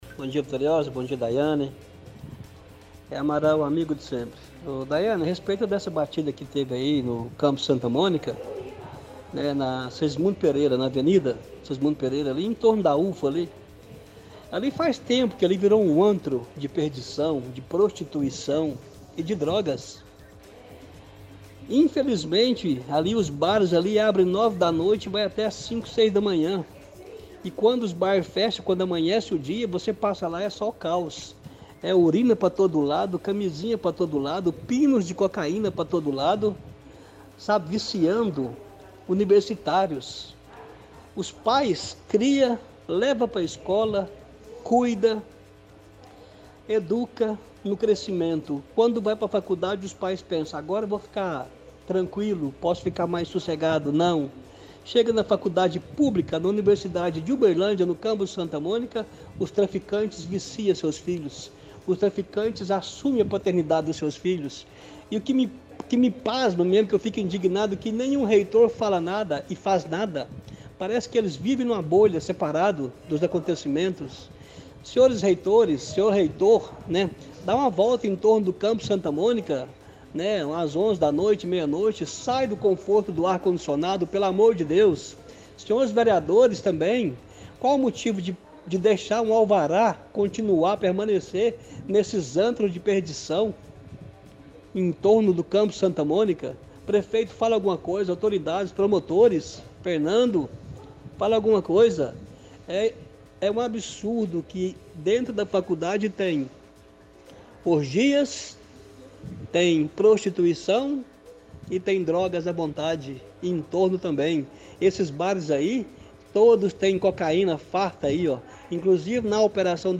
– Ouvinte reclama que bares ao redor do campus da UFU é um “antro de perdição”, reclamando de uso de drogas e prostituição.